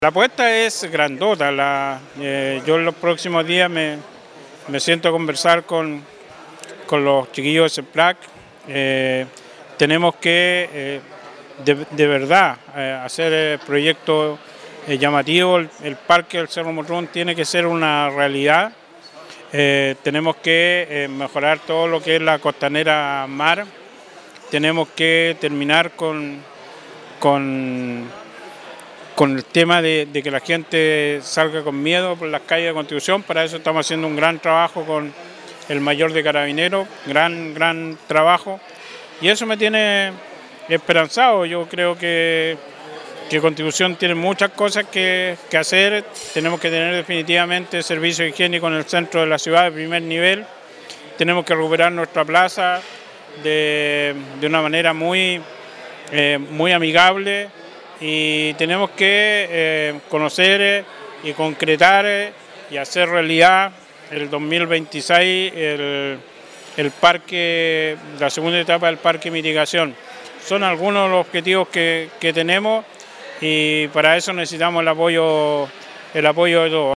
El mensaje fue reforzado por el alcalde Carlos Valenzuela, quien sostuvo tras la actividad: